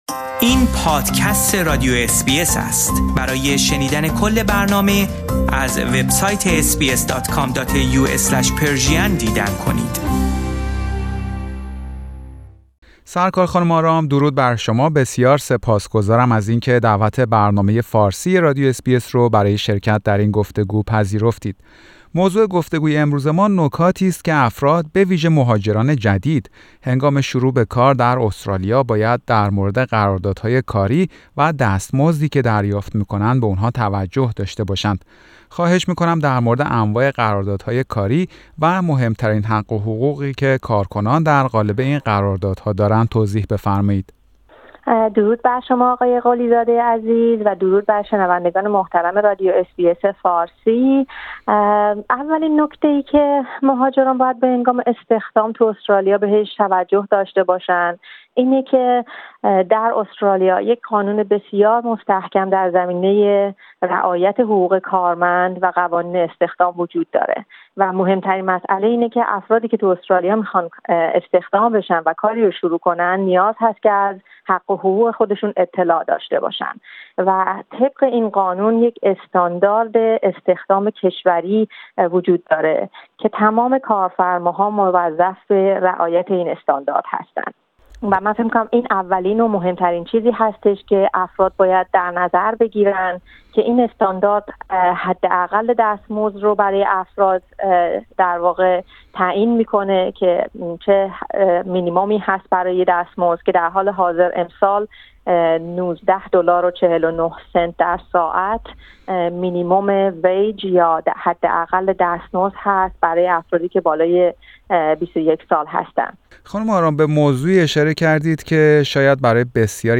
در گفتگو با برنامه فارسی رادیو اس بی اس